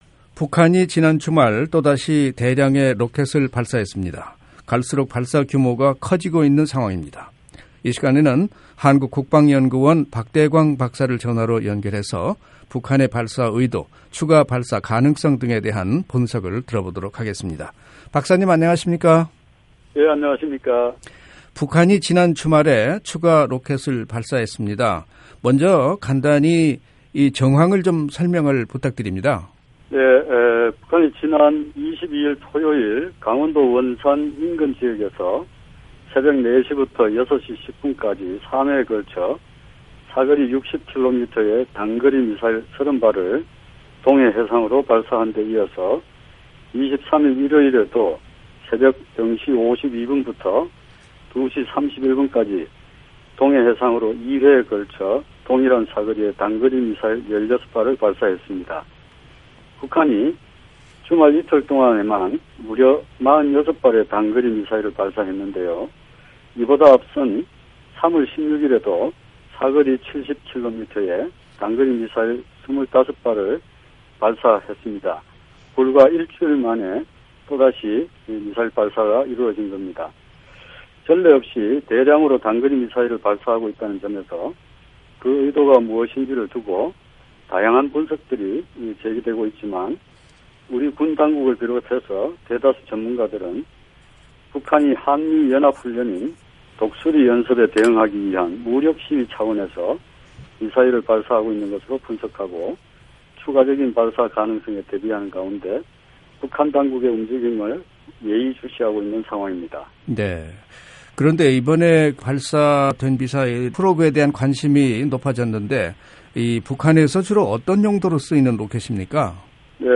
인터뷰